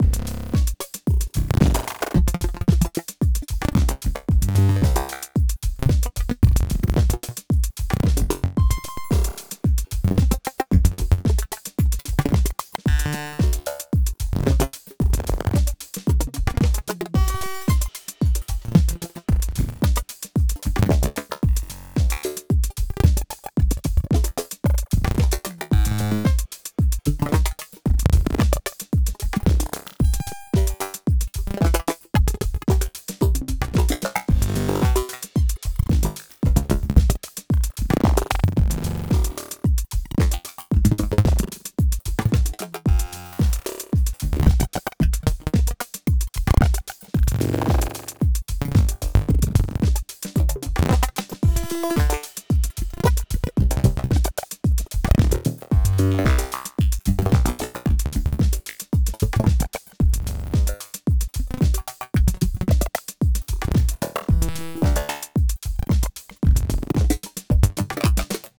For a tiny little box, its remarkably deep and flexible (in a very MakeNoise way)
Before I returned it I sampled the living be cheeses out of it, both multi-sampled, and a load of long freeform noise generating.